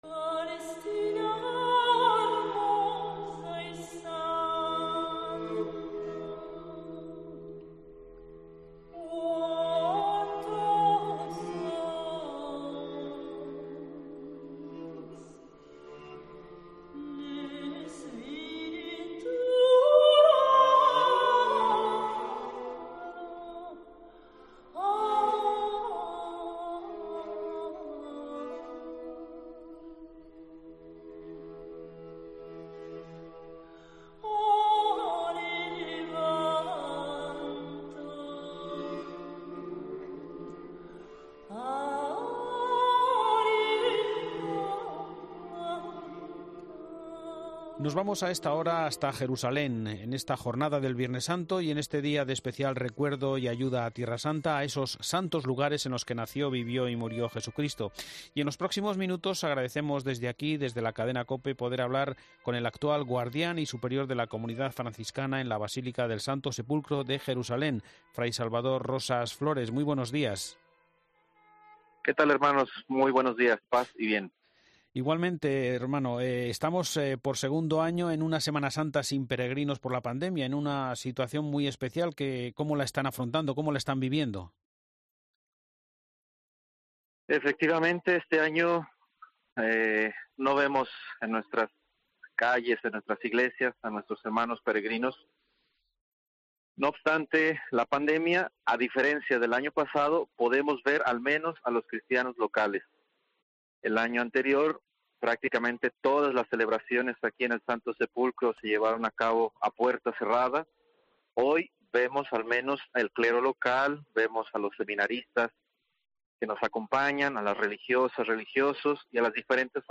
En la programación especial de Semana Santa en la Cadena COPE